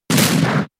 Kick_2
kick-2.mp3